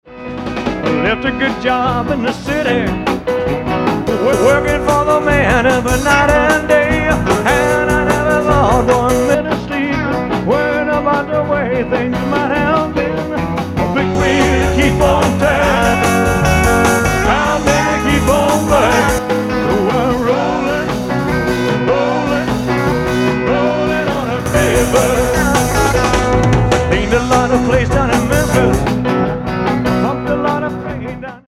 in stereo and with great quality master.
concert audio comparison
which sounds thin and muffled